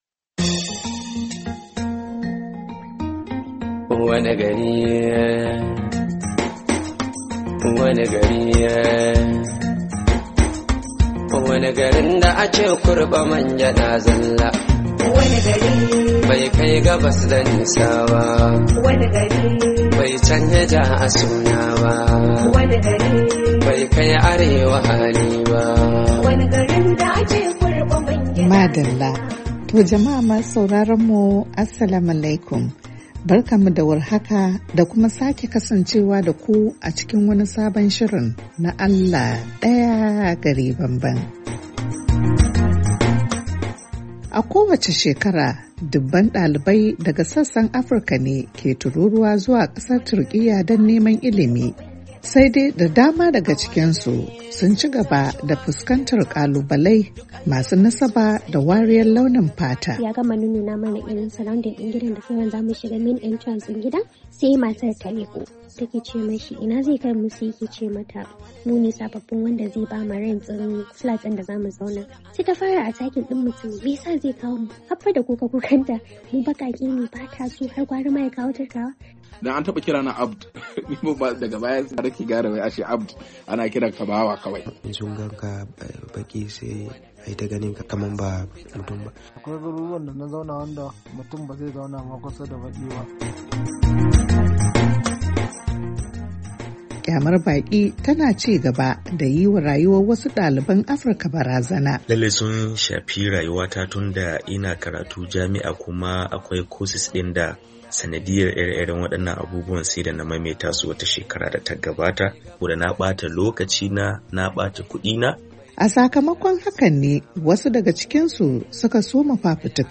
Shirin Allah Daya Gari Bambam na wannan makon ya tattauna ne kan batun zargin nuna wariyar launin fata da ke ci gaba da addabar bakaken fata a Turkiyya. A dalilin haka, mun tattauna da wasu daliban Afirka da ke gwagwarmayar yakar wannan matsala a kasar.